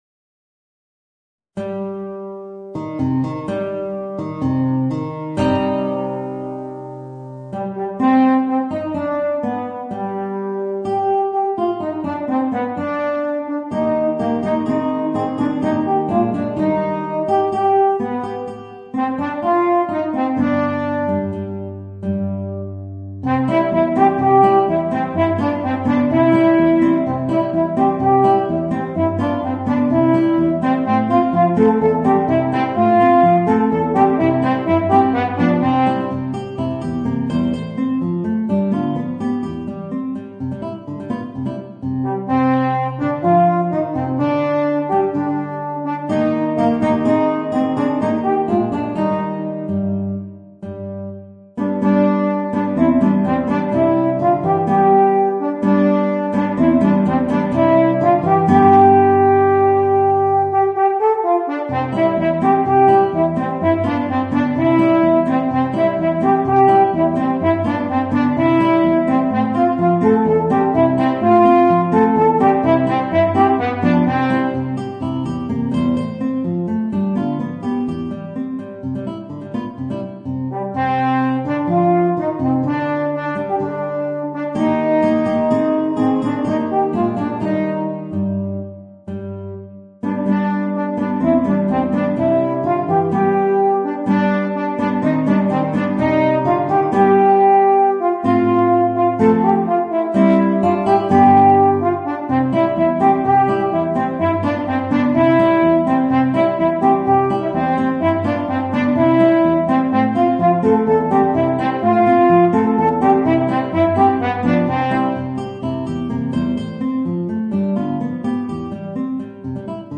Voicing: Guitar and Eb Horn